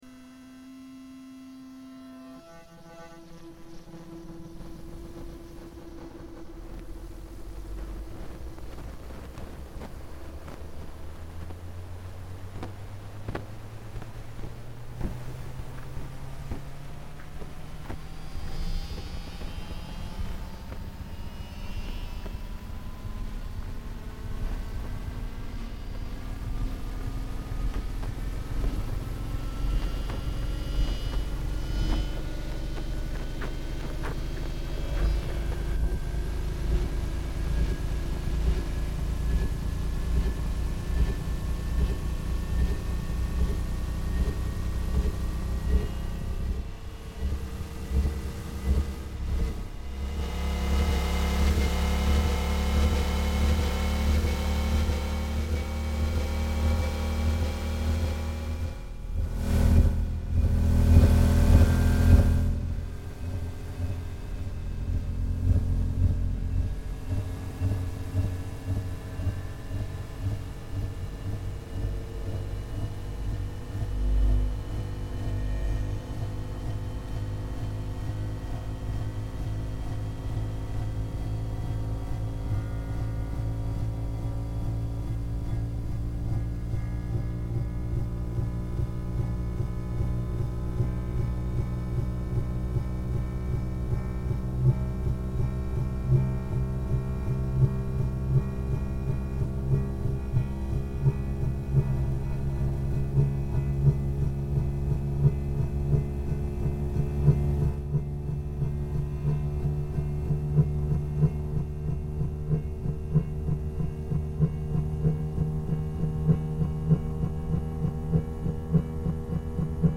Electromagnetic recording of Shanghai Maglev train from Longyang Rd. Station to Pudong Airport station.